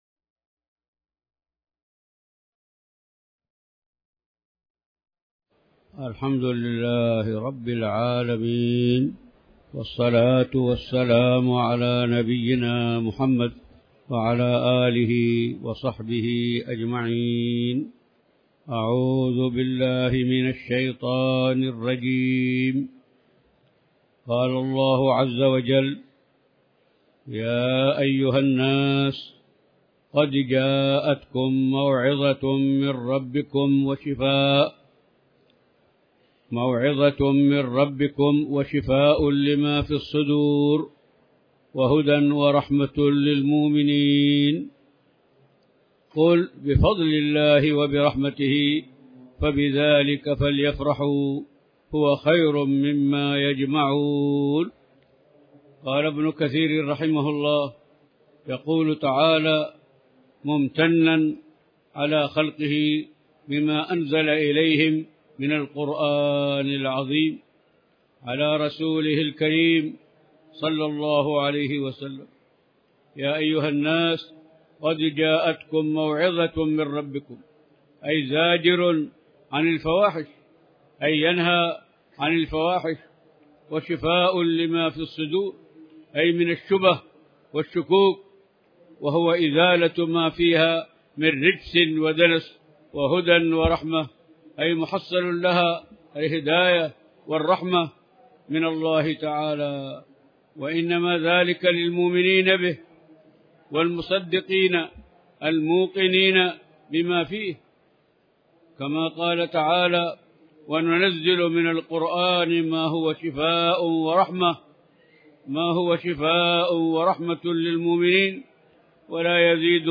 تاريخ النشر ٢٨ ذو الحجة ١٤٣٩ هـ المكان: المسجد الحرام الشيخ